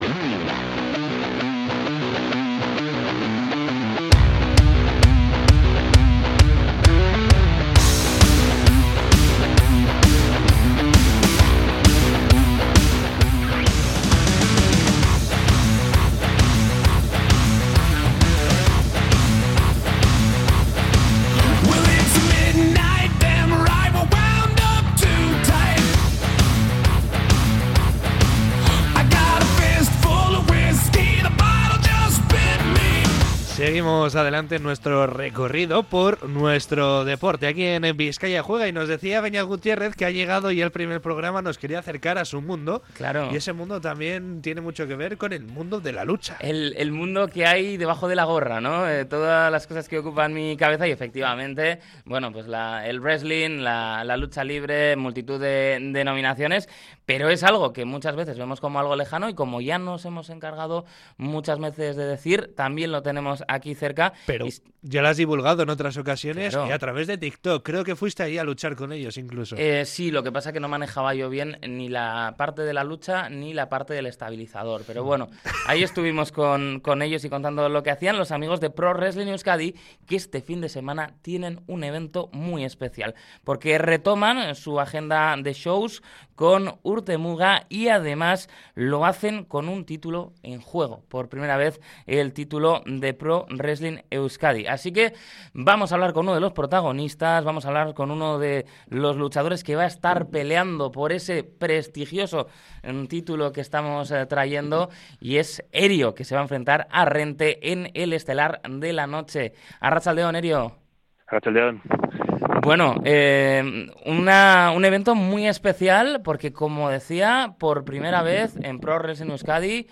En Bizkaia Juega hemos hablado con uno de los wrestlers que intentará hacerse con la victoria en esta histórica pelea